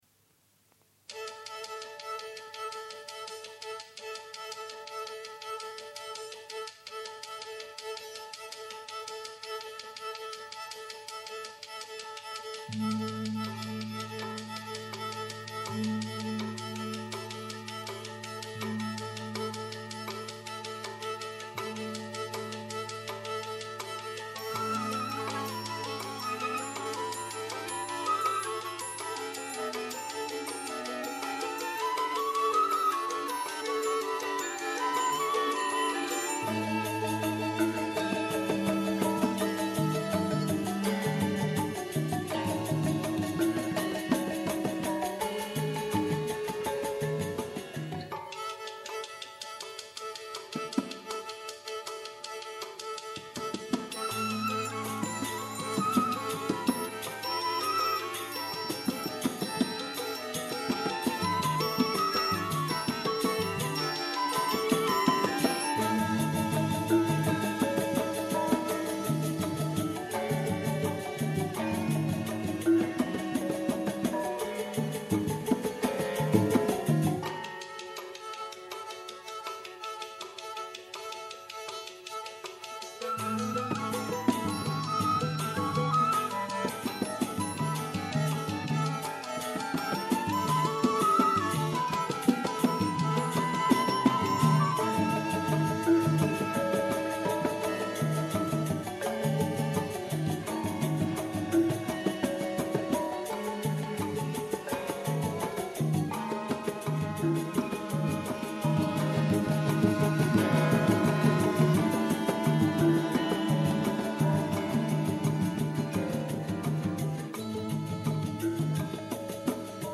BALI